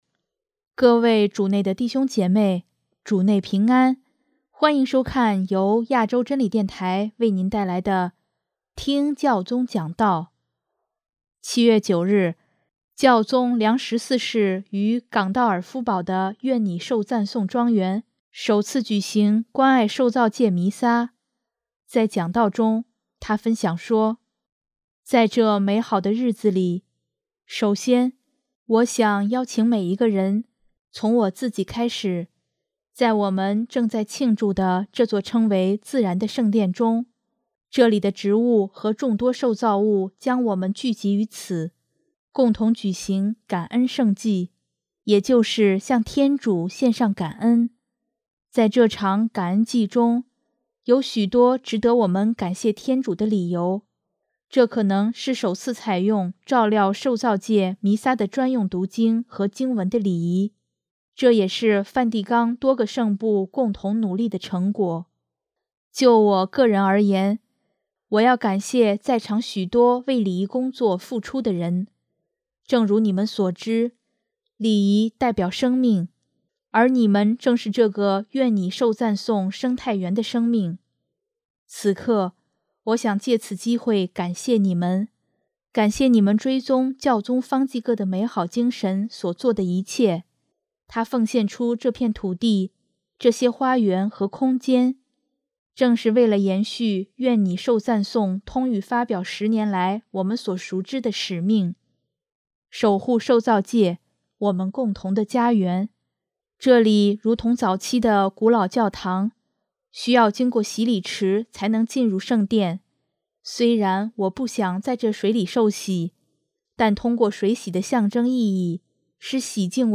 【听教宗讲道】|守护受造界—我们共同的家园
7月9日，教宗良十四世于冈道尔夫堡的愿祢受赞颂庄园（Borgo Laudato Sì）首次举行“关爱受造界弥撒”，在讲道中，他分享说：